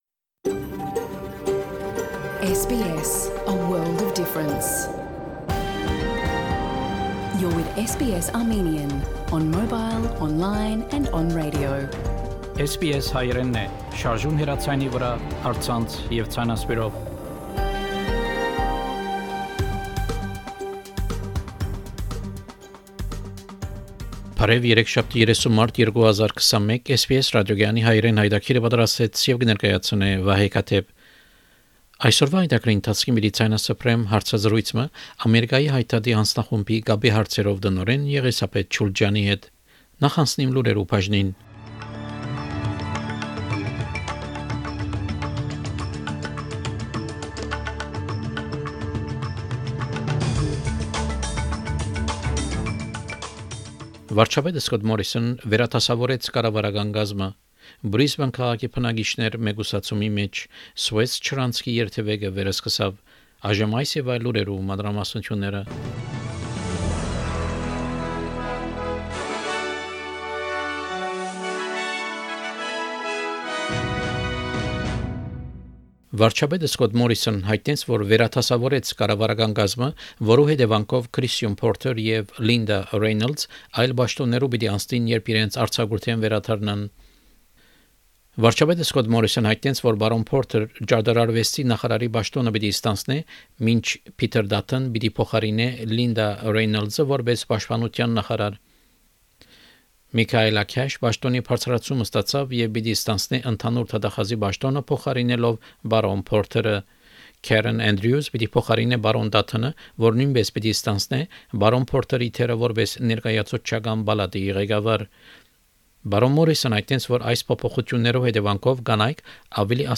SBS Armenian news bulletin – 30 March 2021
SBS Armenian news bulletin from 30 March 2021 program.